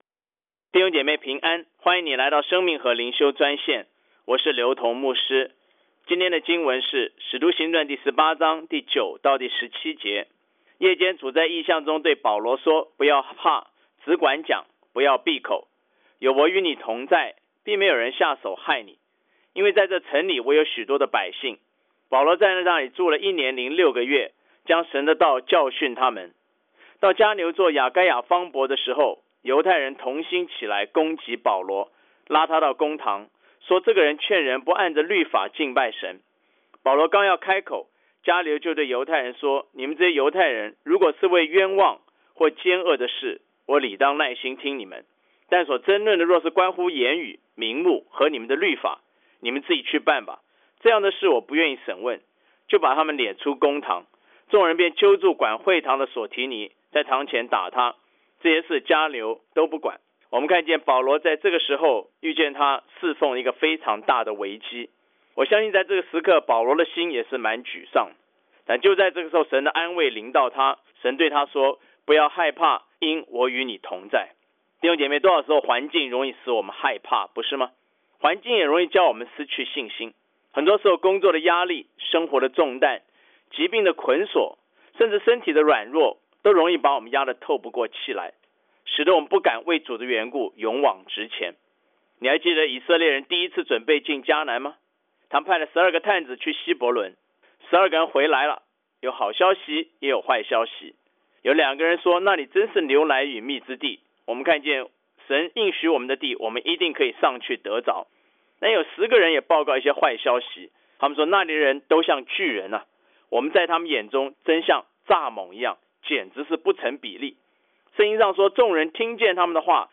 藉着每天五分钟电话分享，以生活化的口吻带领信徒逐章逐节读经。